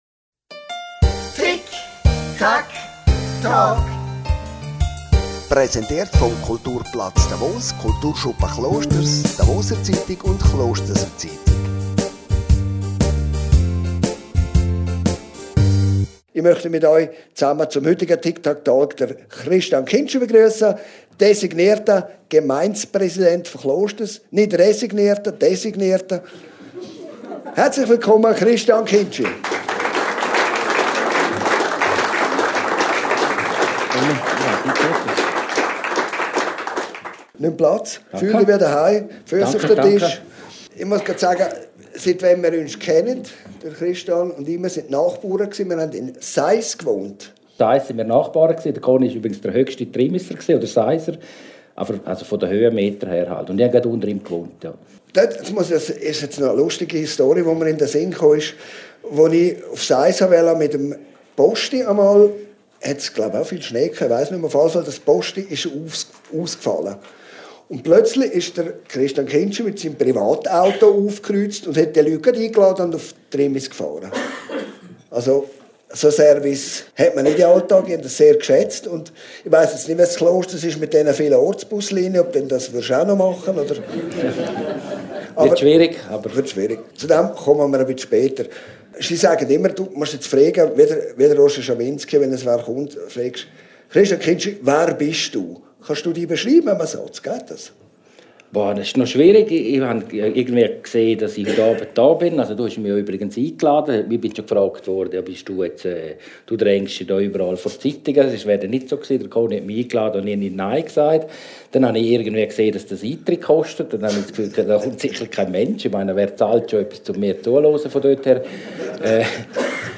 Talkshow und Podcast, präsentiert von der «Klosterser Zeitung» und dem Kulturschuppen Klosters, der «Davoser Zeitung» und dem Kulturplatz Davos. Gast ist der neu gewählte Klosterser Gemeindepräsident Christian Kindschi.